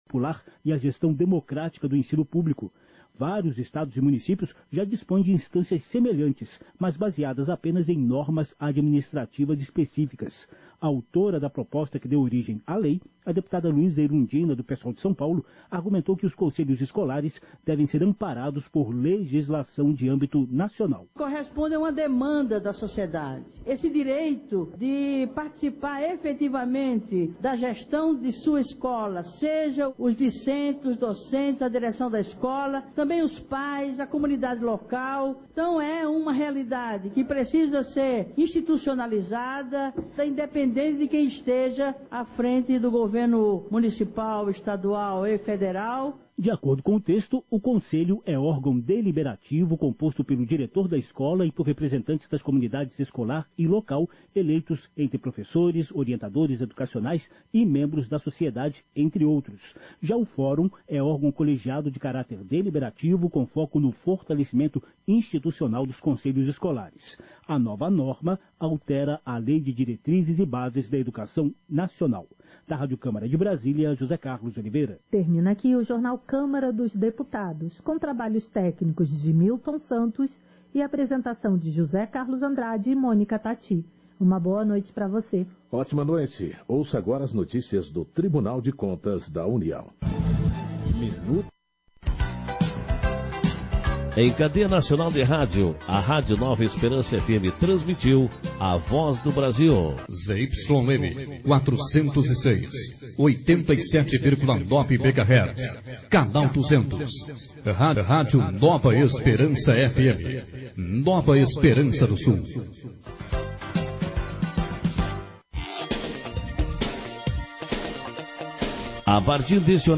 Sessão Ordinária 25/2023